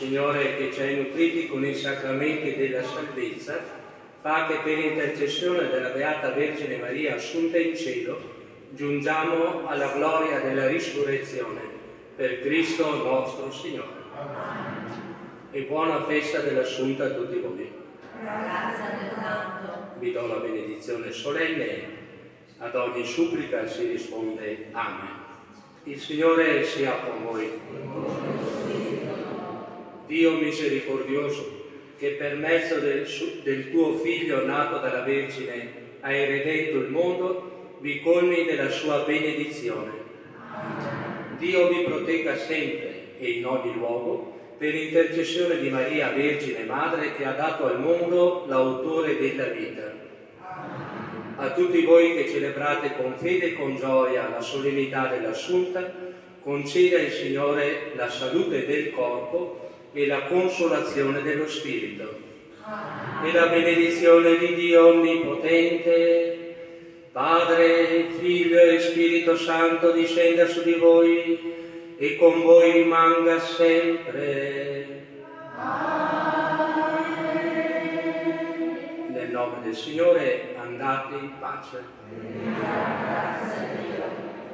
Benedizione Solenne
SFM--benedizione-assunta-2022.mp3